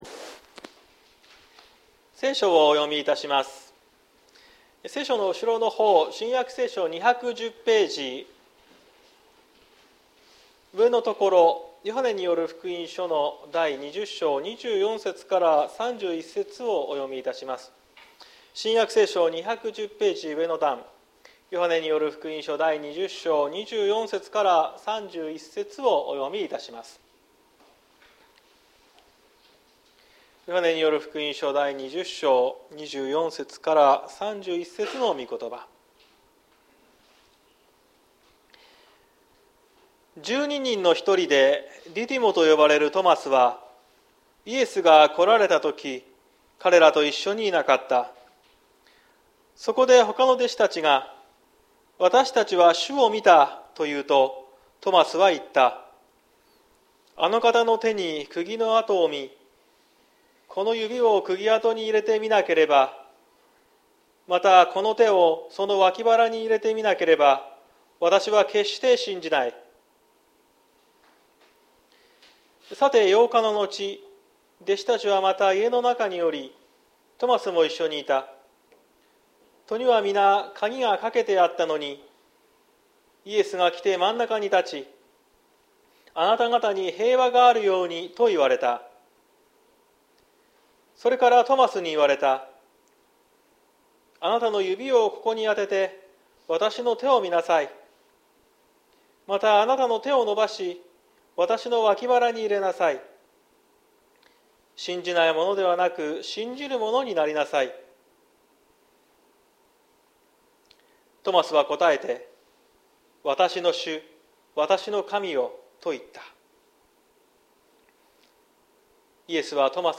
2023年04月09日朝の礼拝「見ないのに信じる幸い」綱島教会
綱島教会。説教アーカイブ。